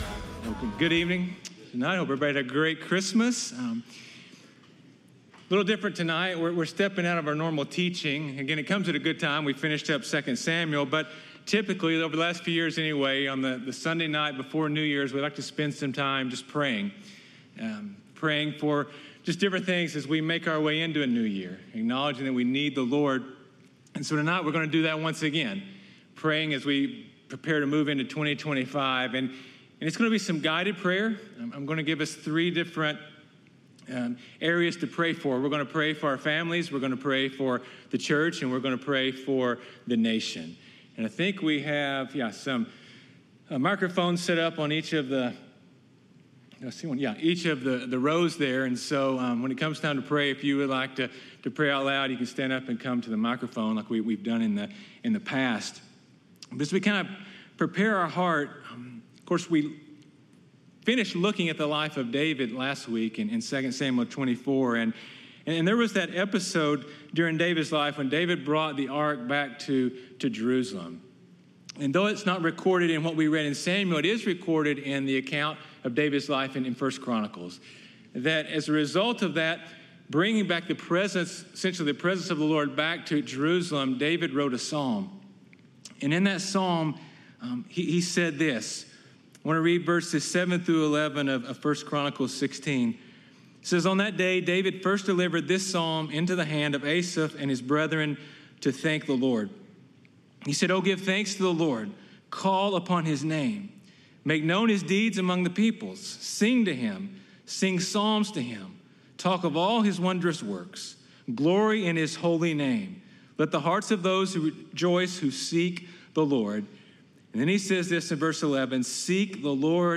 Prayer and Worship Night